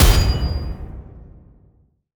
rank-impact-pass.wav